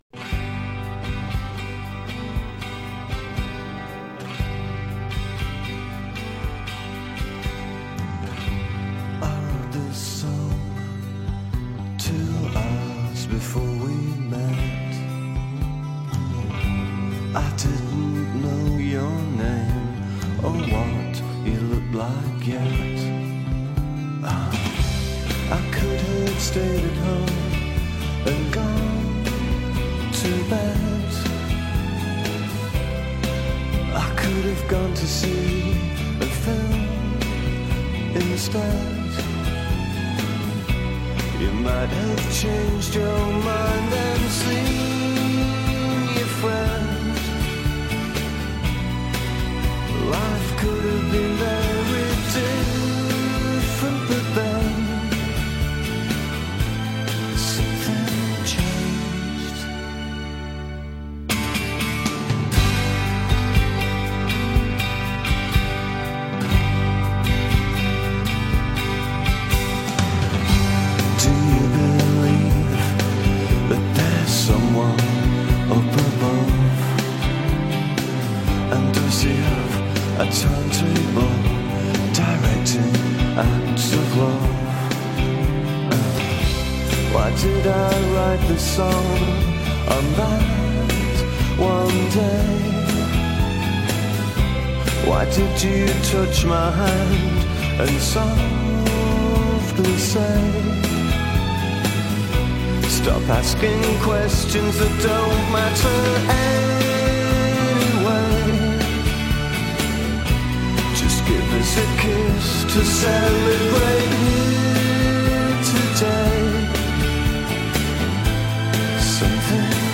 שעה ספונטנית של שירים חורפיים מכל השנים ומכל הסגנונות, עם טיפה סיפורים (כי אי אפשר בלי) ועם הרבה אווירה 🙂 ופה בעצם נולדה התכנית הראשונה של “ספונטני”, בכל שישי ב-3 בצהריים, שבה בכל שבוע עולה לשידור ספונטני מישהו אחר מצוות השדרנים של רדיו פלוס לשעה בלתי מתוכננת של מוסיקה מעולה שהוא אוהב.